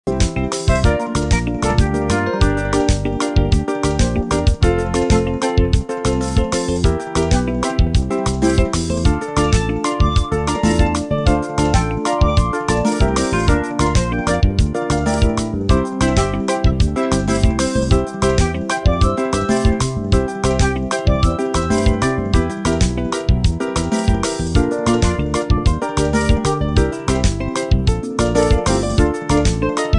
在 MusicFX 輸入自己喜歡的音樂風格或者情境後，AI 就會生成出兩段 30 秒~ 70 秒的音軌且完全免費。